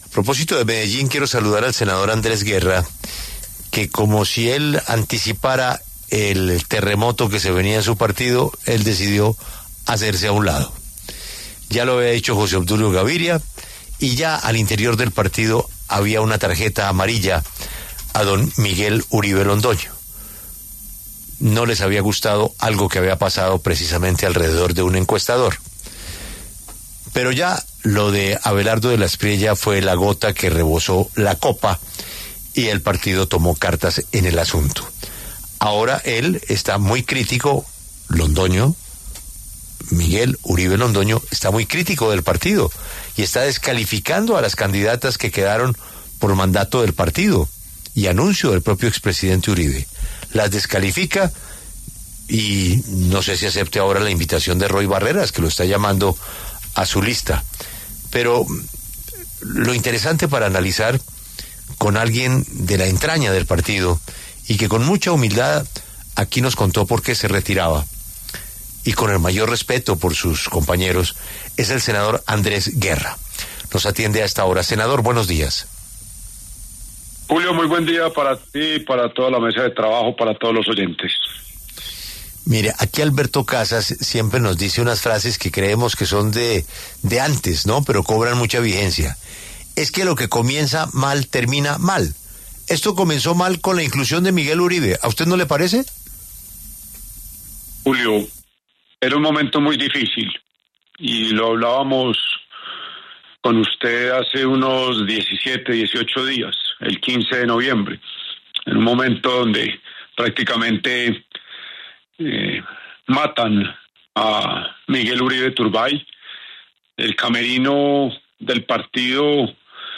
Apropósito de la pelea en el Centro Democrático, el senador Andrés Guerra pasó por los micrófonos de La W.